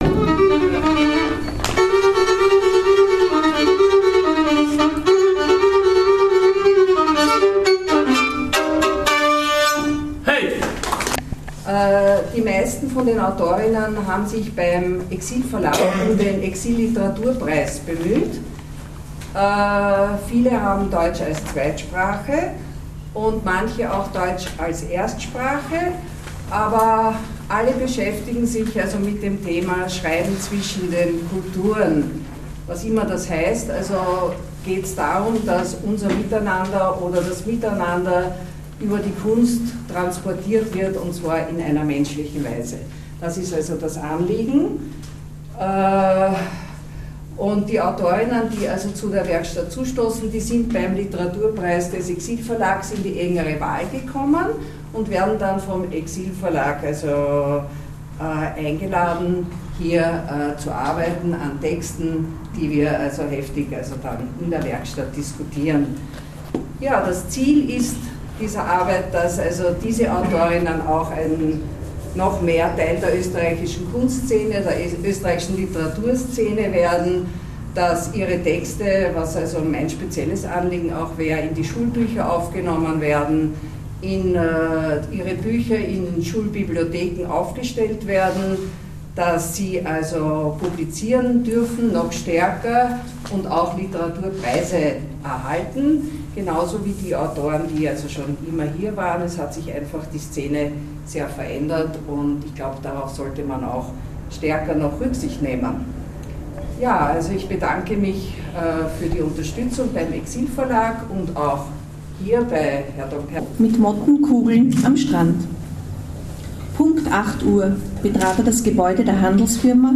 Lesung der Zwischenkulturellen Schreibwerkstatt in der VHS Hietzing am 13.6.2012